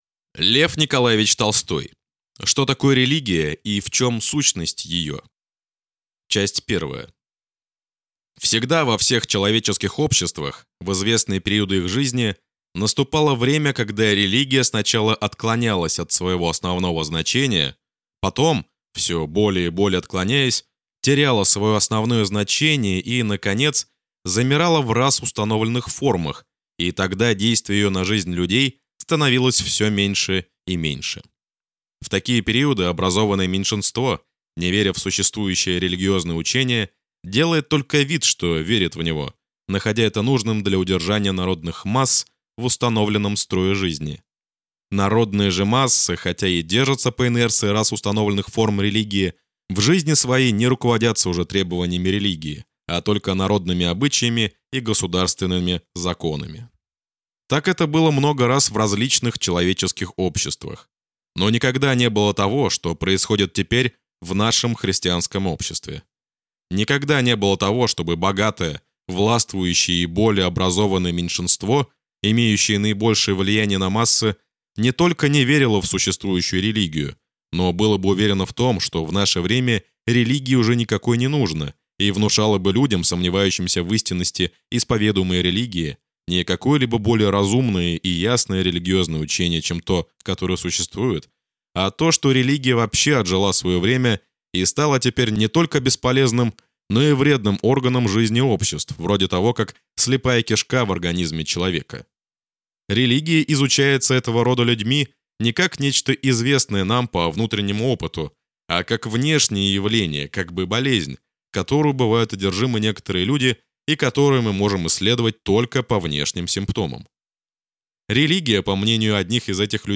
Аудиокнига Что такое религия и в чем сущность ее | Библиотека аудиокниг